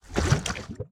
step_lava2.ogg